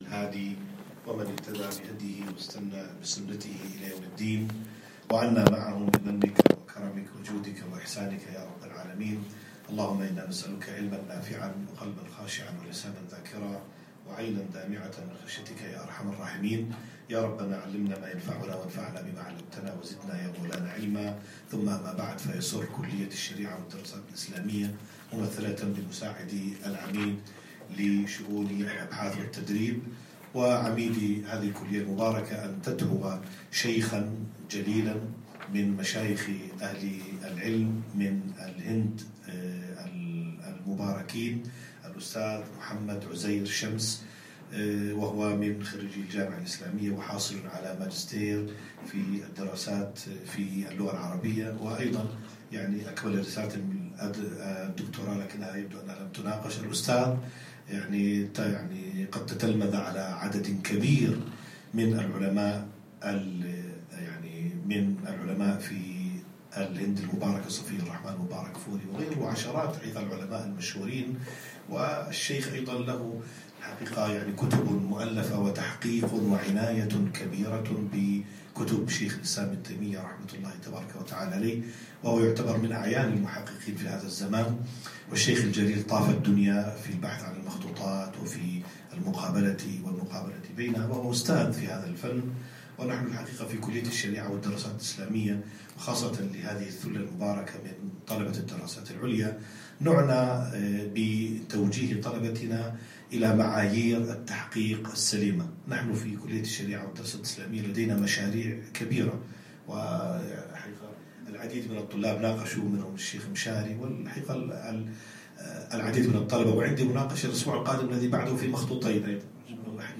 تحقيق المخطوط - محاضرة بجامعة الكويت